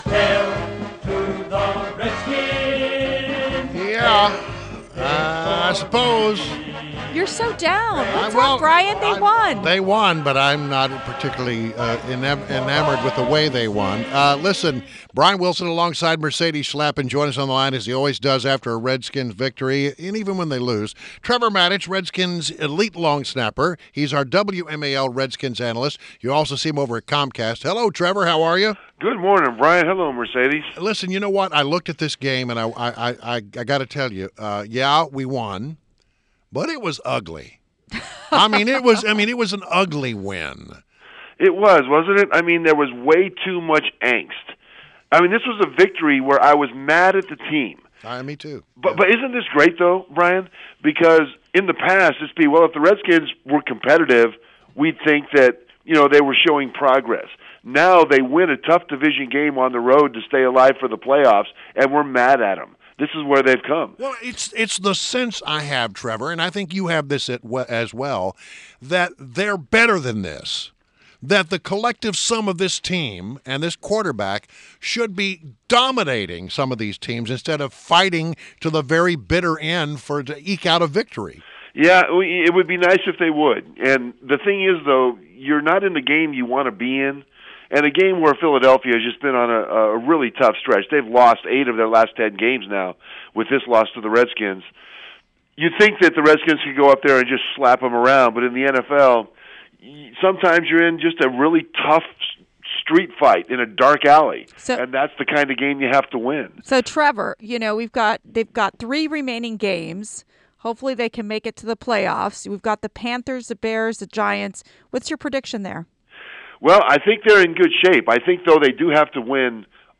WMAL Interview - TREVOR MATICH - 12.12.16
INTERVIEW -- TREVOR MATICH -- Redskins elite long snapper, WMAL's Redskins analyst and Comcast SportsNet co-host